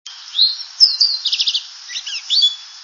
Brown Thrasher
thrasher_doing_towhee_730.wav